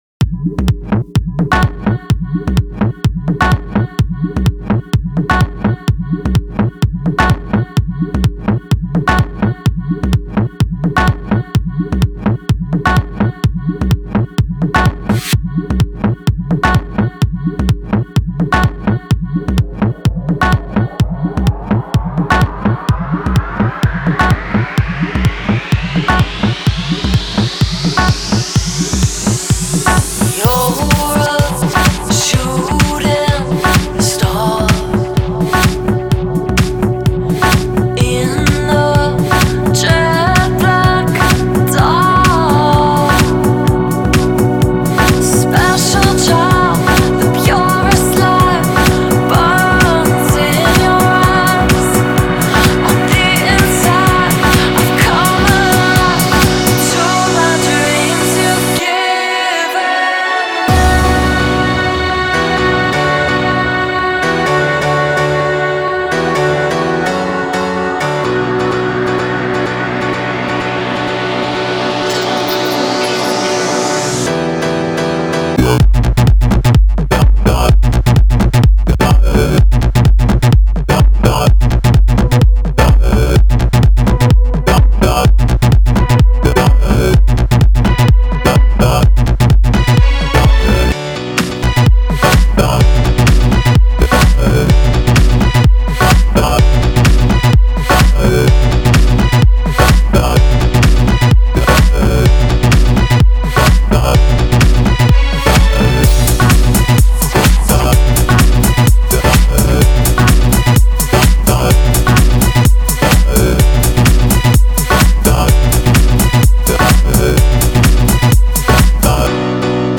Жанр:House